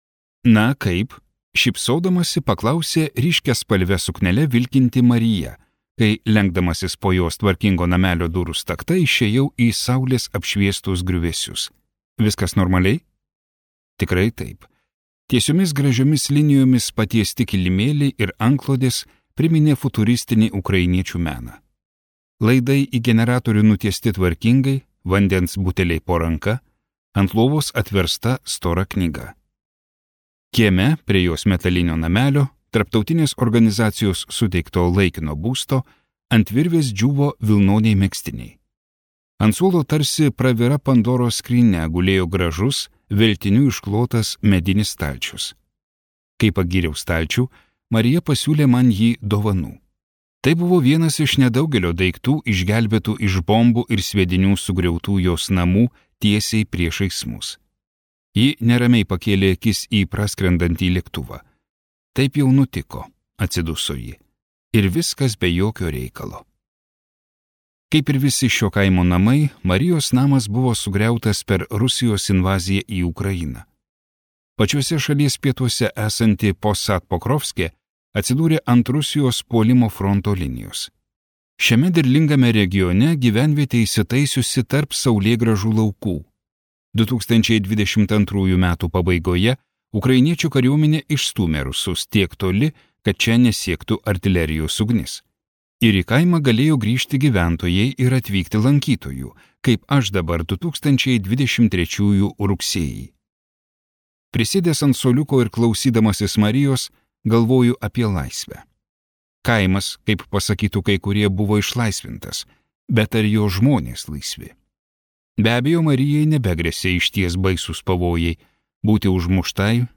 Timothy Snyder audioknyga „Apie laisvę“ analizuoja tikrosios laisvės supratimo nykimą ir kokios iš to kyla krizės.